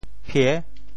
鳔（鰾） 部首拼音 部首 鱼 总笔划 19 部外笔划 11 普通话 biào 潮州发音 潮州 piê6 文 中文解释 鳔 <名> 鱼鳔 [air bladder]。